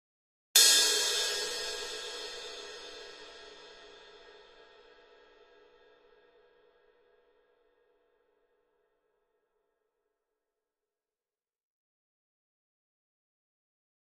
Cymbal, Small, Single Hit, Type 3